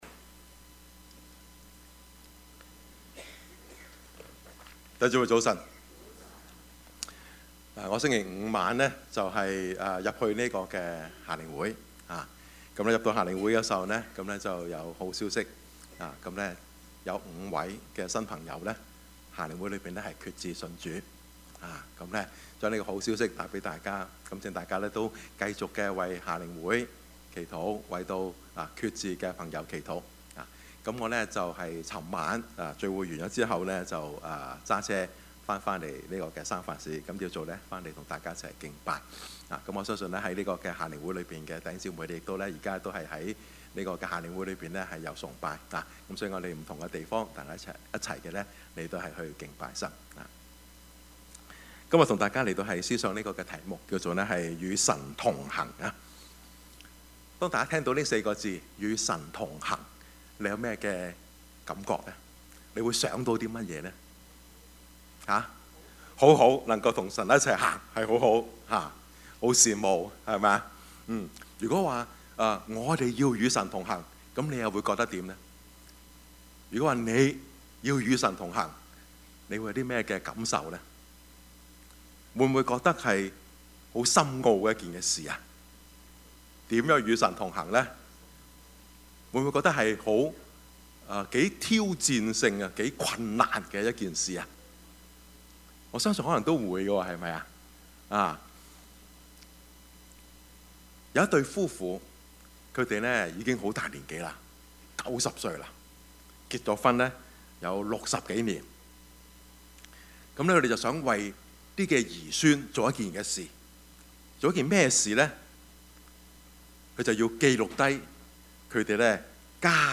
Service Type: 主日崇拜
Topics: 主日證道 « 金錢以外 挪亞的葡萄園 »